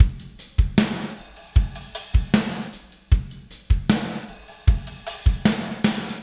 Spaced Out Knoll Drums 03 Fill.wav